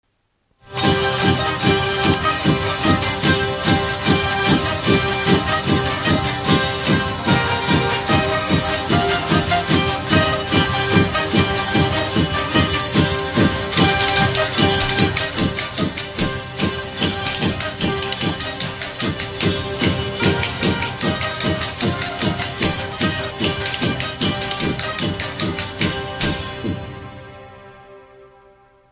Dallampélda: Hangszeres felvétel
Alföld - Bihar vm. - Létavértes
citera
köcsögduda
Műfaj: Oláhos
Stílus: 7. Régies kisambitusú dallamok
Kadencia: 1 (1) b3 1